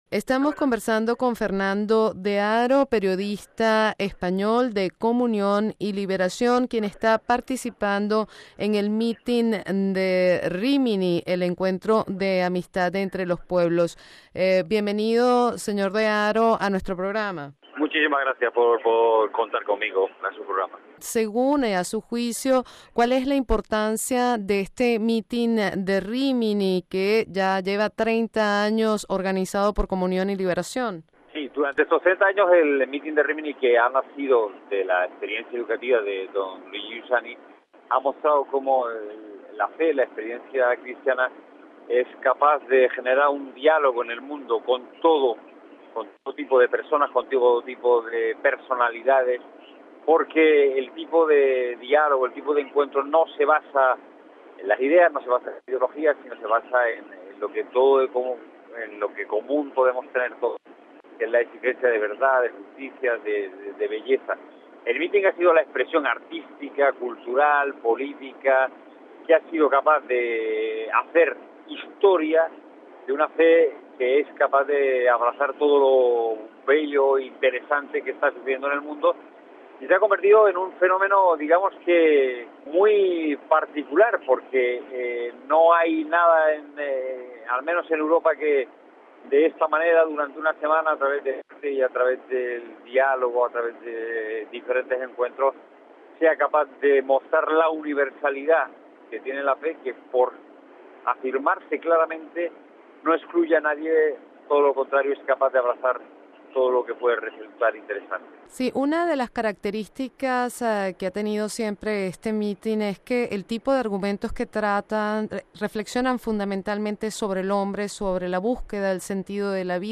Domingo, 23 ago (RV).- El lema 2009 del Encuentro para la Amistad entre los Pueblos –“El conocimiento es siempre un acontecimiento”-, se propone acoger la exhortación de Benedicto XVI a recuperar el valor del conocimiento y de la razón. Como explica el periodista español de Comunión y Liberación